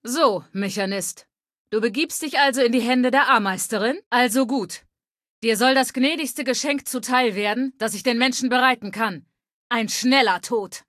Datei:Femaleadult01default ms02 greeting 000c553c.ogg
Fallout 3: Audiodialoge